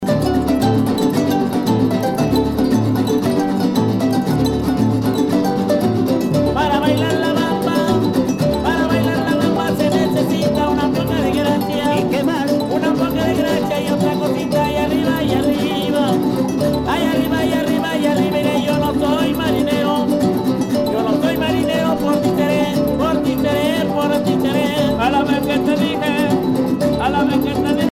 danse : bamba
Pièce musicale éditée